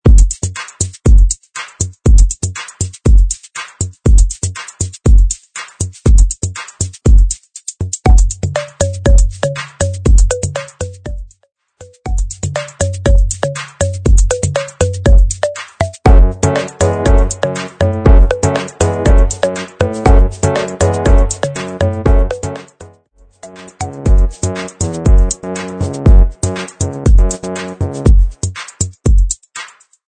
Groove